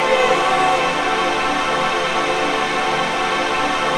VOICEPAD03-LR.wav